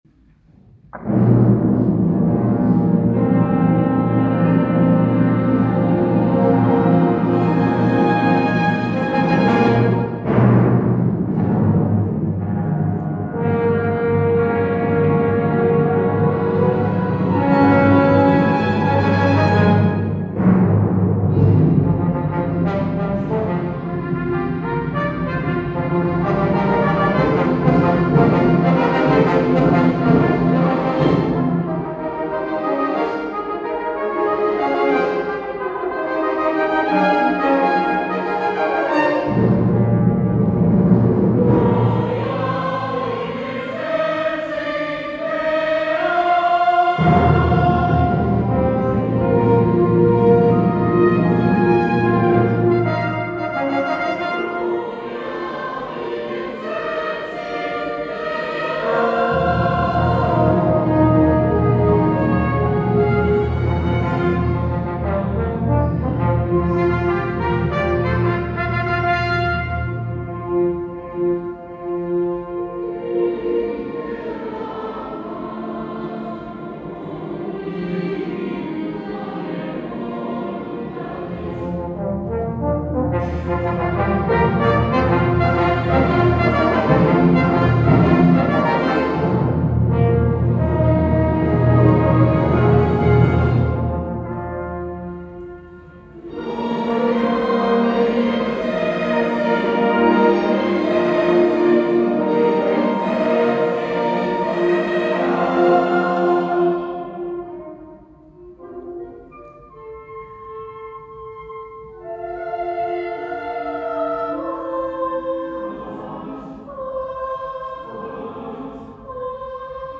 ♫ Coro de voces mixtas y Orquesta de Cámara
La Camerata Cantabile es una agrupación de La Adrada compuesta por un coro de unas 25 voces mixtas y una orquesta de cámara.